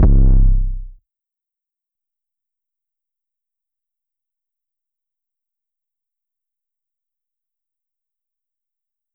808 (Count Up).wav